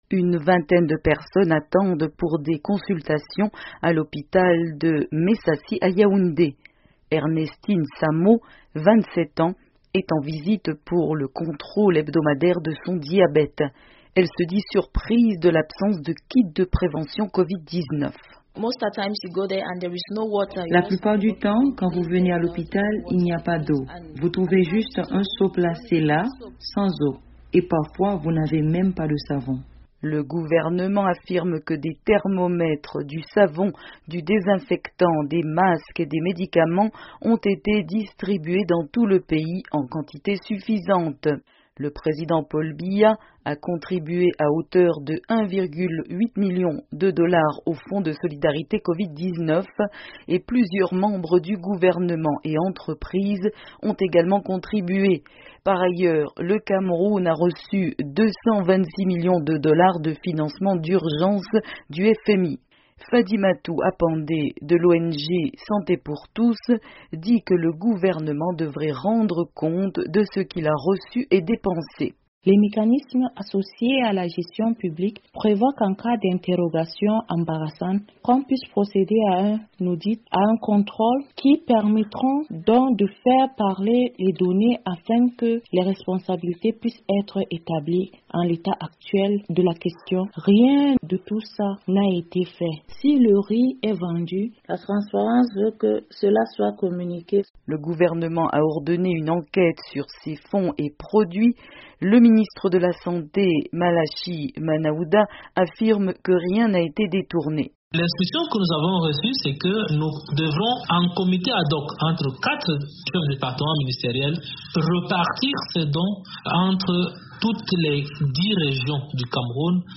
Le Cameroun a ordonné des enquêtes sur la gestion du fonds de solidarité Covid-19 auquel ont contribué des ci-vils, suite aux accusations d'associations affirmant qu'une grande partie de ces fonds auraient été détournés, no-tamment 4000 sacs de riz destinés aux patients vendus illégalement. Un reportage